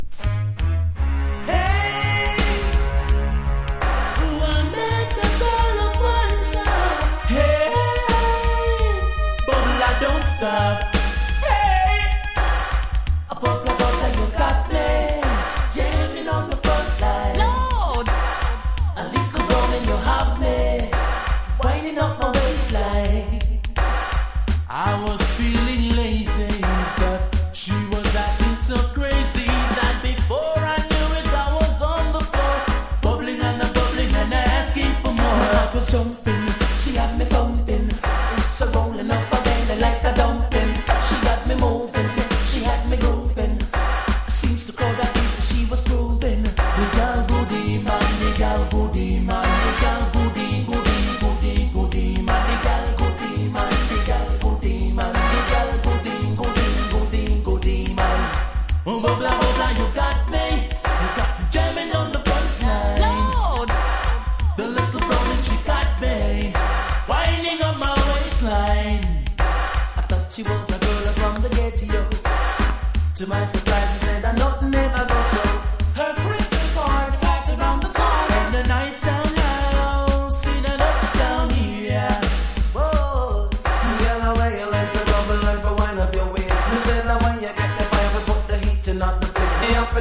1. REGGAE >